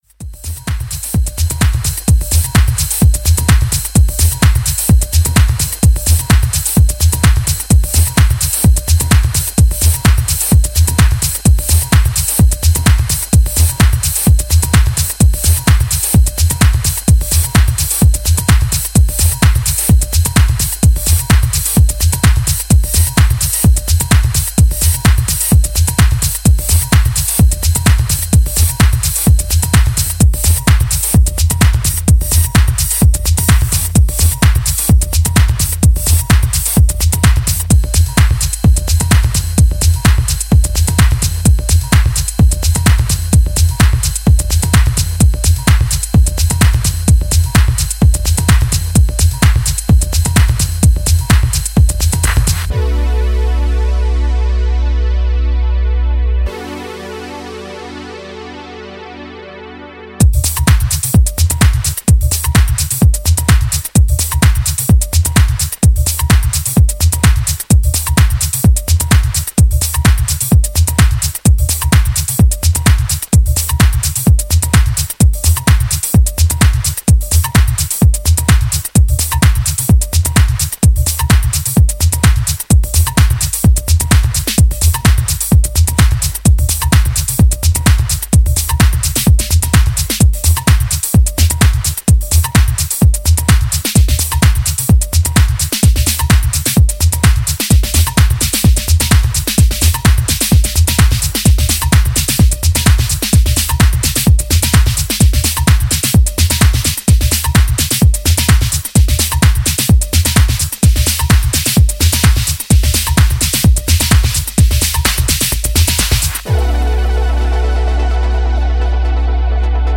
プロッギーなテイストのツールトラック路線のA面2曲もバッチリ即戦力。